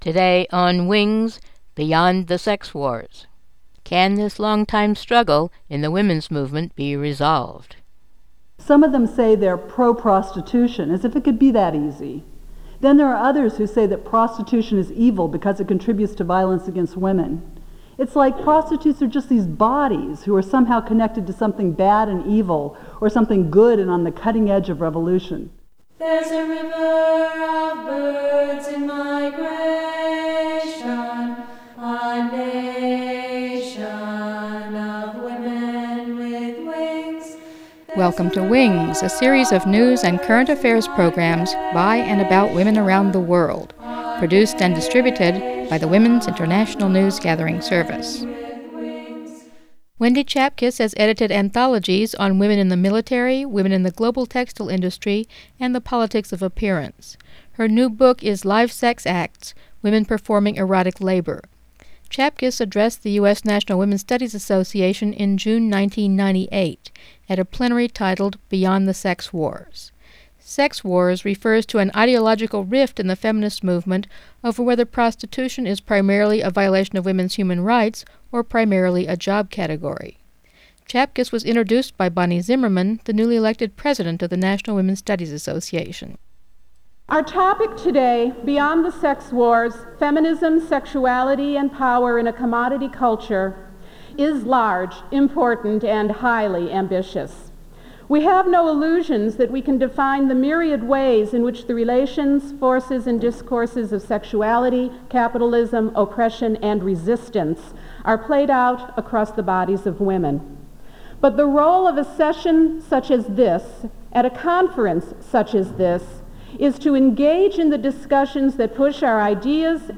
NWSA plenary talk